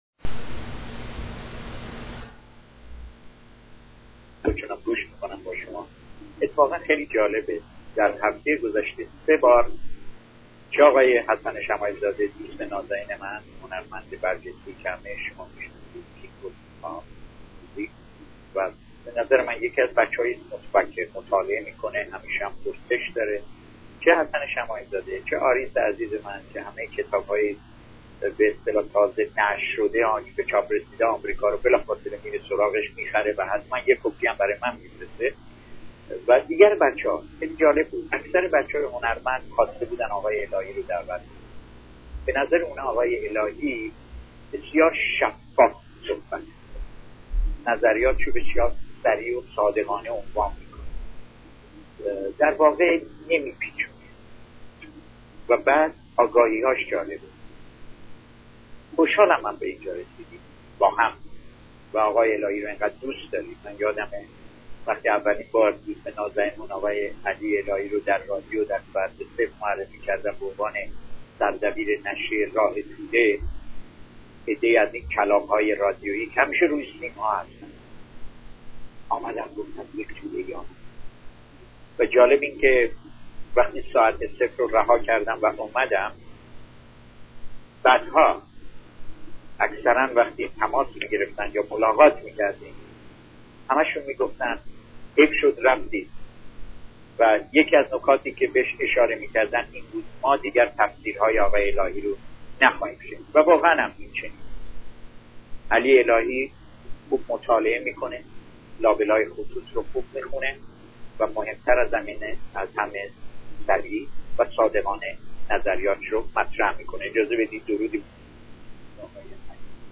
این مصاحبه برای یکی از تلویزیون های فارسی زبان لس آنجلس ضبط و پخش شد، زیرا مصاحبه کننده، در آن زمان، از رادیو ایران بیرون آمده و با آن شبکه تلویزیونی همکاری می کرد. زمانی این گفتگو انجام شد که دولت احمدی نژاد بر سر کار آمده و دوران دوم ریاست جمهوری محمدخاتمی به پایان رسیده بود. محمد خاتمی سفر کوتاهی به امریکا کرده بود و رسانه های فارسی زبان لس آنجلس جنجالی تازه را بر سر این سفر برپا کرده بودند.